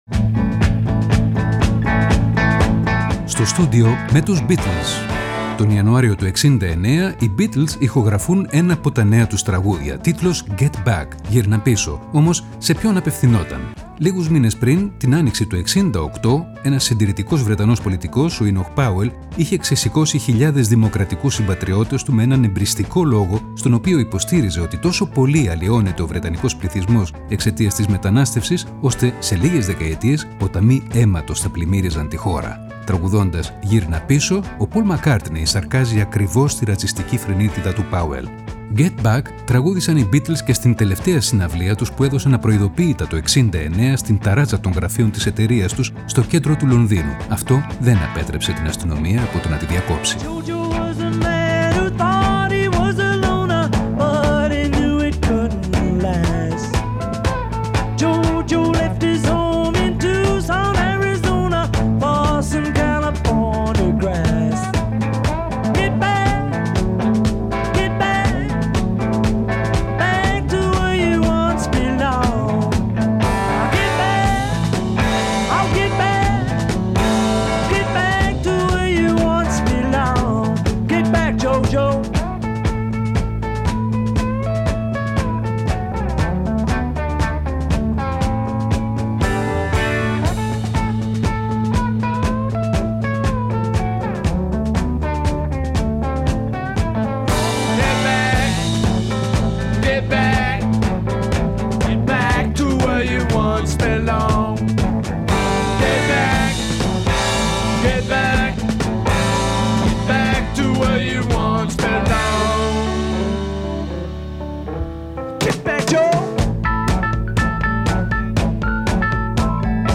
σε δοκιμαστική ηχογράφηση